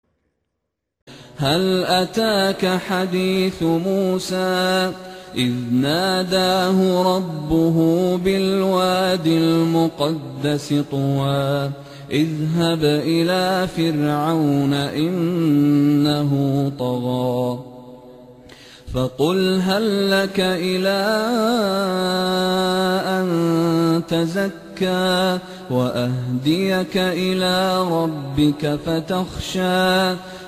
Upaya untuk menghafal Al-Qur'an, dapat dibantu melalui cara mendengarkan bacaan Al-Qur'an secara berulang-ulang.